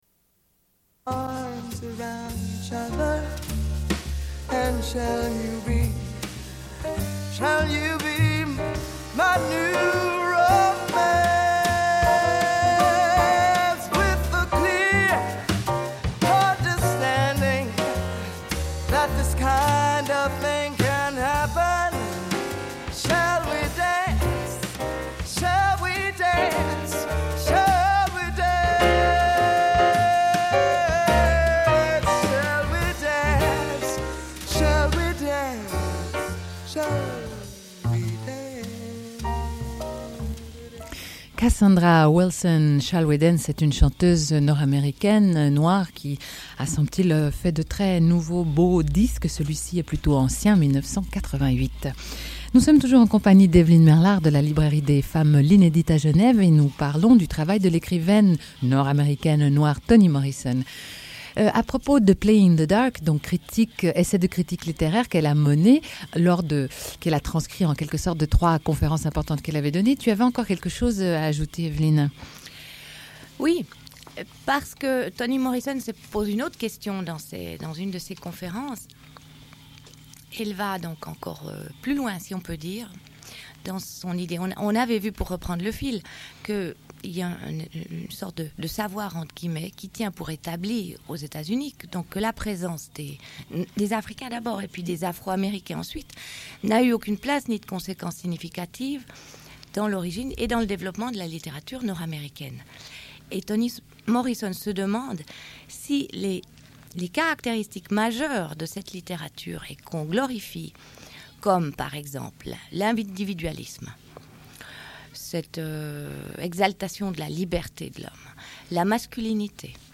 Une cassette audio, face B28:54